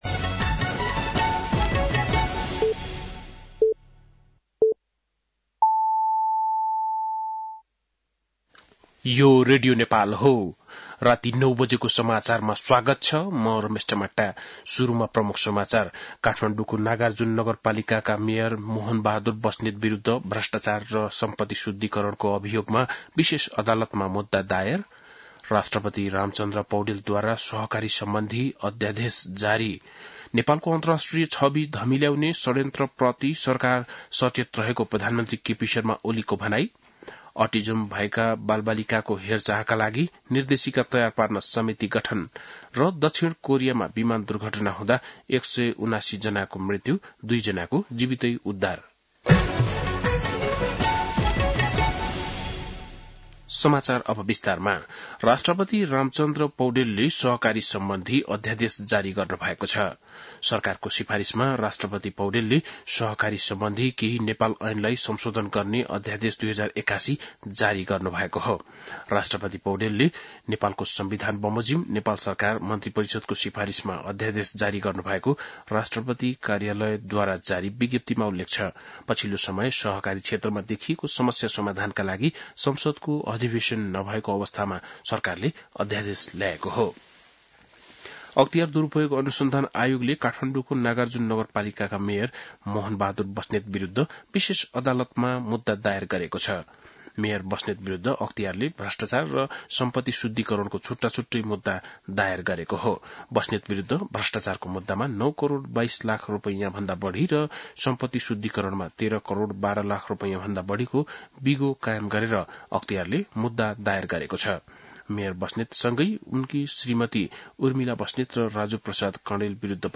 बेलुकी ९ बजेको नेपाली समाचार : १६ पुष , २०८१
9-PM-Nepali-News-9-14.mp3